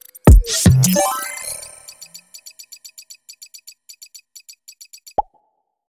Jingle 1